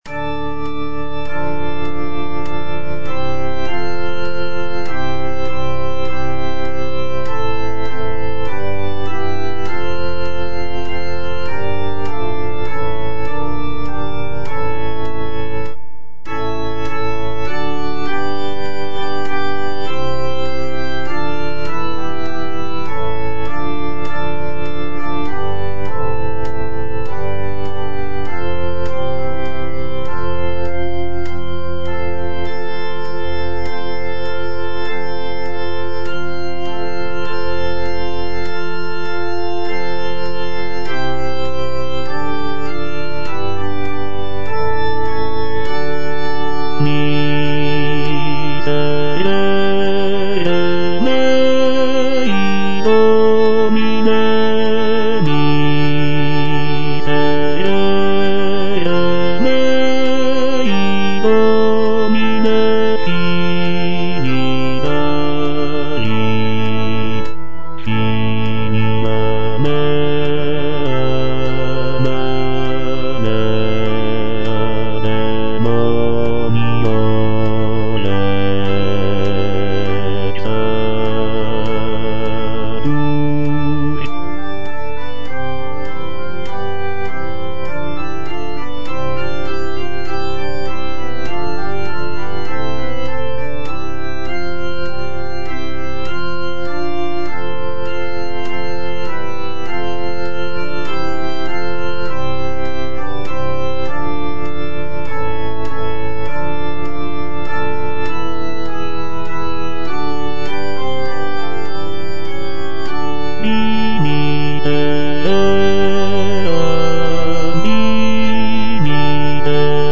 G. DE WERT - EGRESSUS JESUS Tenor II (Voice with metronome) Ads stop: auto-stop
"Egressus Jesus" is a sacred choral composition written by Italian composer Giaches de Wert in the late 16th century.
"Egressus Jesus" showcases de Wert's mastery of polyphony, with intricate vocal lines weaving together to create a rich and expressive musical tapestry.